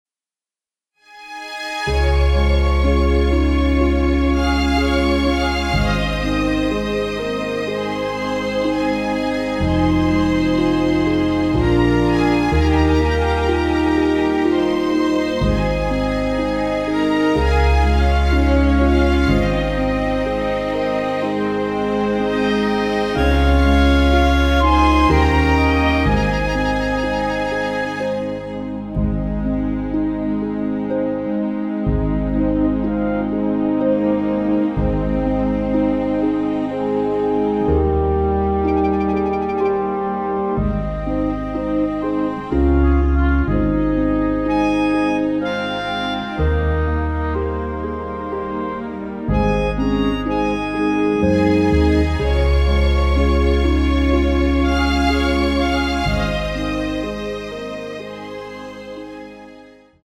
오케스트라 버젼으로 편곡 MR입니다..
진행은 전주후 1절, 간주, 2절 식으로 진행이 됩니다.
Ab
앞부분30초, 뒷부분30초씩 편집해서 올려 드리고 있습니다.